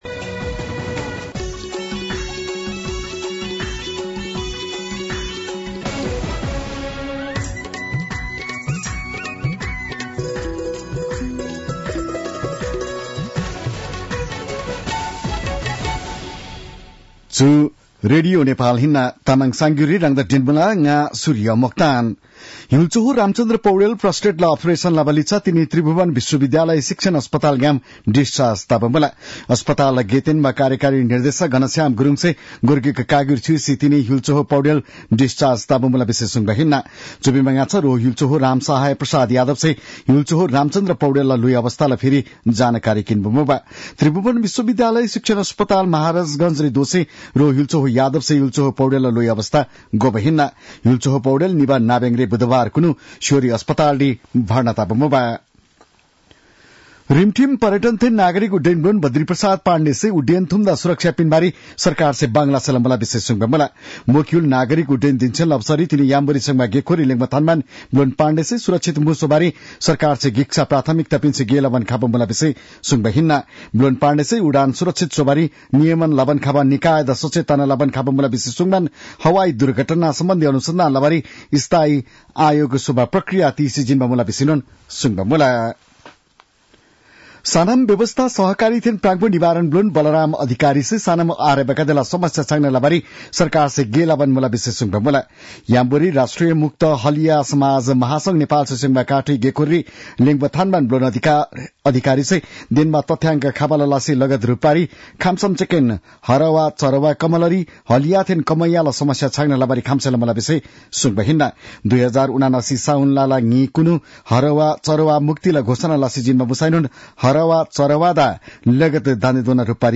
An online outlet of Nepal's national radio broadcaster
तामाङ भाषाको समाचार : २३ मंसिर , २०८१